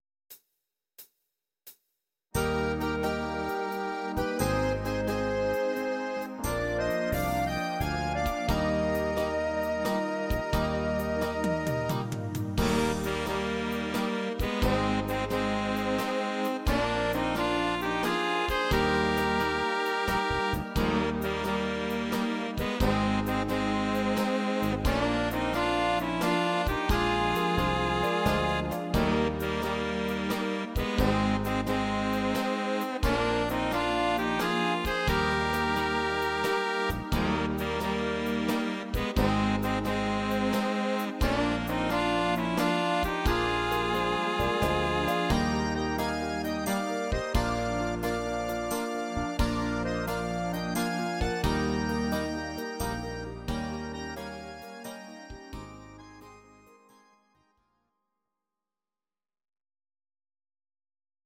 These are MP3 versions of our MIDI file catalogue.
Please note: no vocals and no karaoke included.
Klarinette